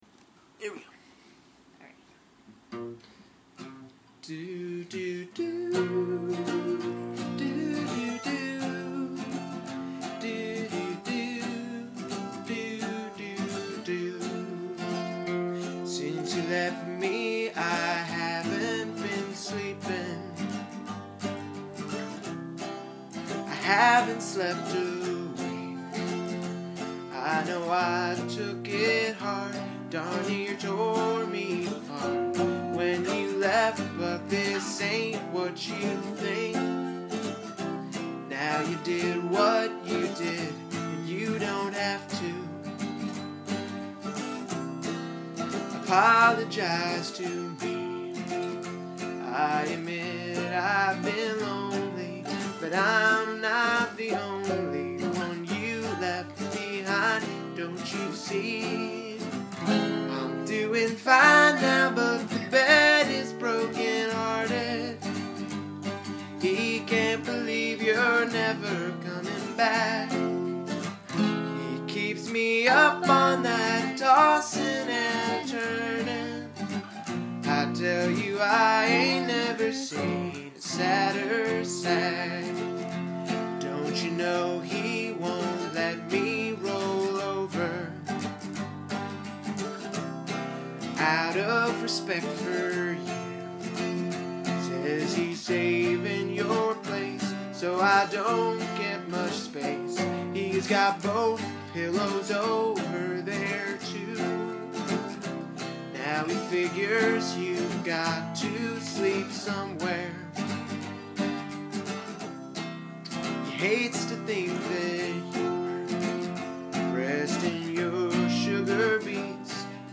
Intro and solo from Brahms' lullaby.